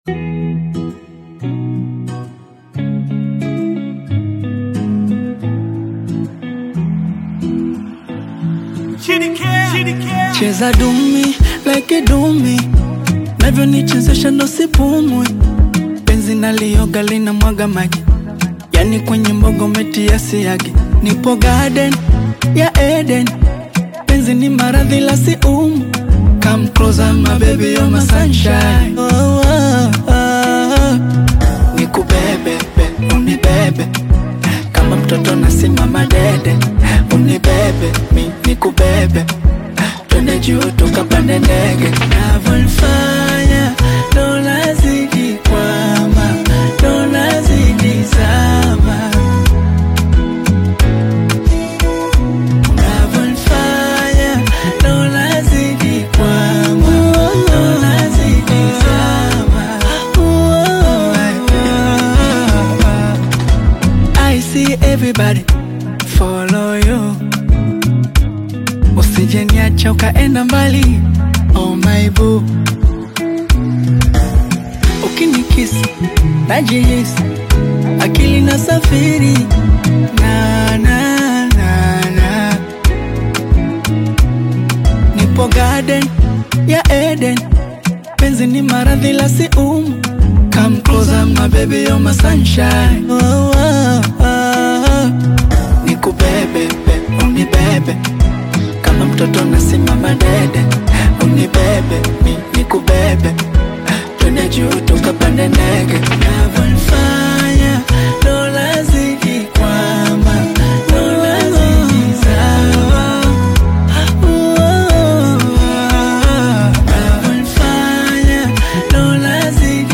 Bongo Flava
soulful vocals and heartfelt delivery